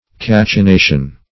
Cachinnation \Cach`in*na"tion\ (k[a^]k`[i^]n*n[=a]"sh[u^]n), n.